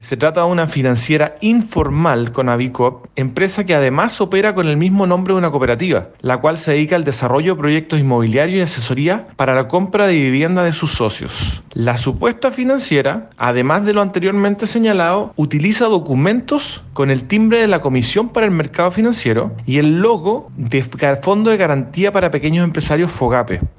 El director regional de Los Lagos, Osvaldo Emhart, explicó que además utilizaban el mismo nombre de una cooperativa conocida en la zona.